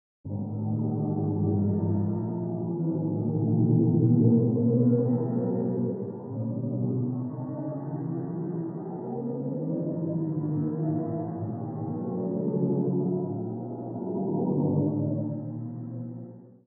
WULA_Black_Hole_Entity_Attached_Sound.wav